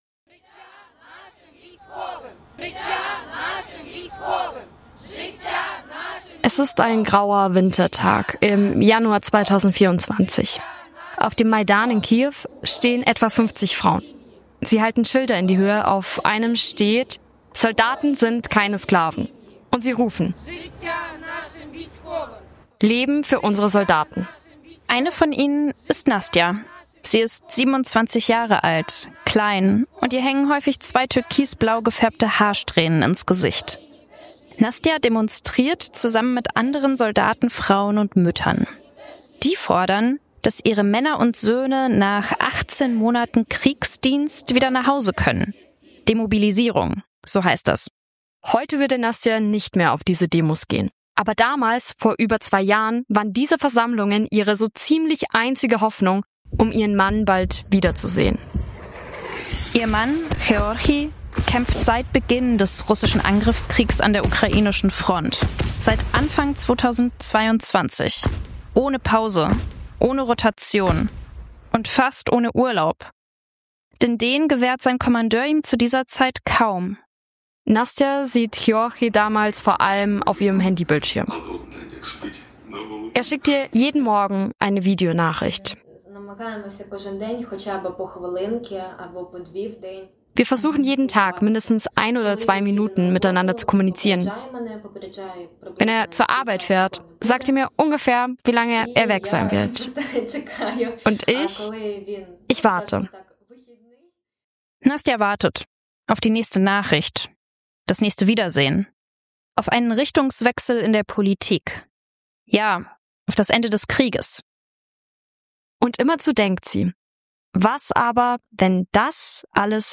Beschreibung vor 2 Monaten Diese Episode ist ein Storytelling-Special: Wir erzählen die Geschichte einer Fernbeziehung.